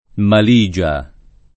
mal&Ja] s. f.; pl. -gie o -ge — antico nome di certe cipolle molto forti; più spesso unito a cipolla come agg.: un mazzuolo di cipolle maligie o di scalogni [